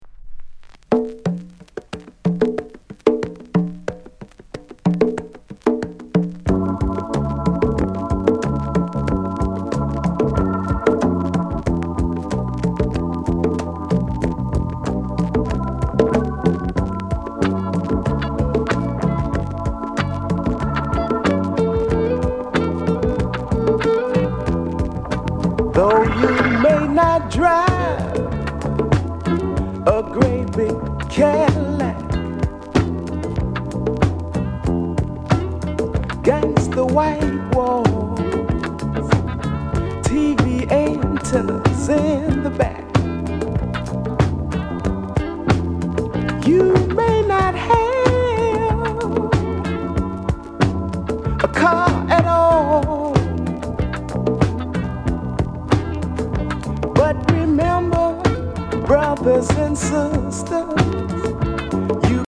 SOUL CLASSICS JAMAICA PRESS